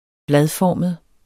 Udtale [ -ˌfɒˀməð ]